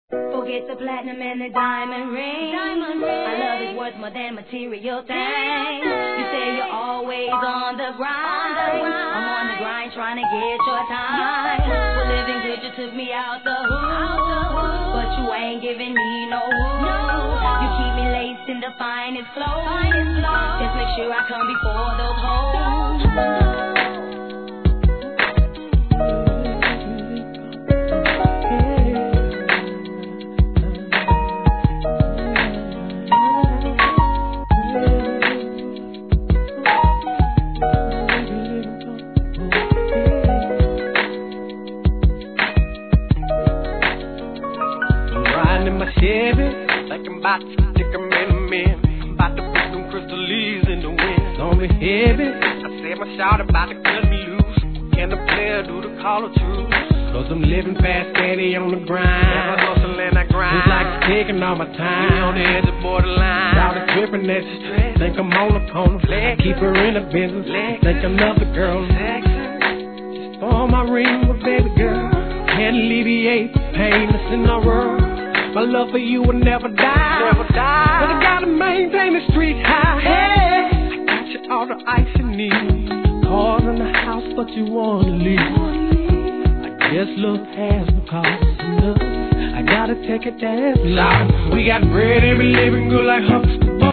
G-RAP/WEST COAST/SOUTH
素晴らしいピアノの哀愁ナンバー!!